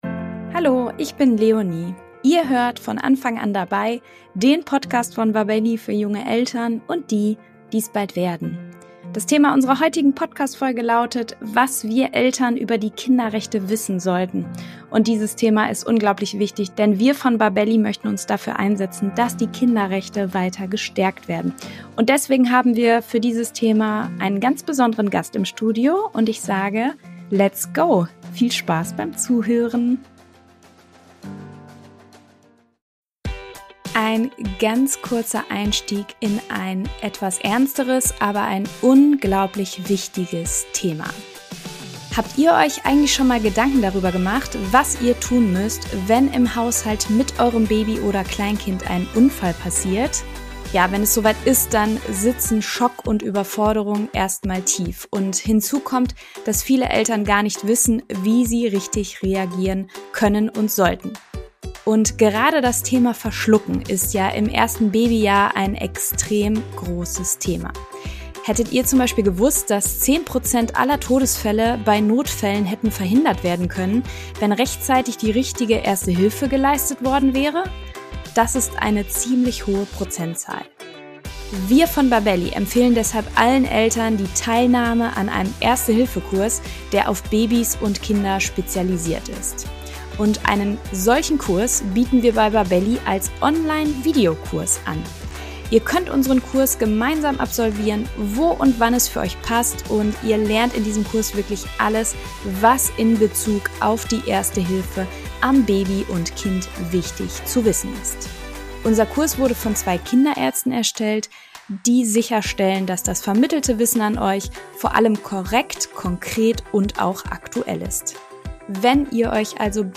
Im Gespräch mit Lehrer